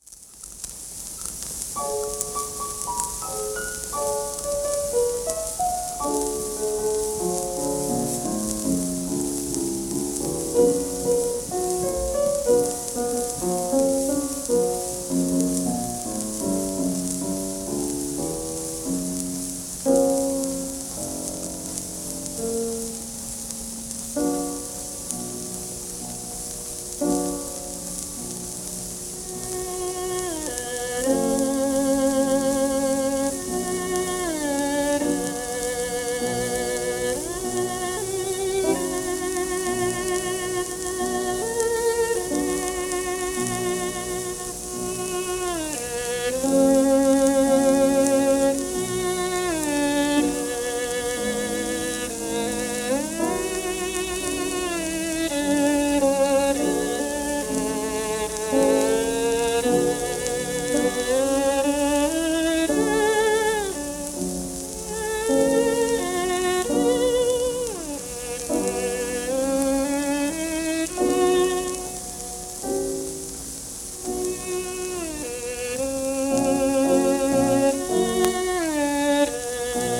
w/ピアノ
盤質A- *軽微な小キズ,盤反り
1928年録音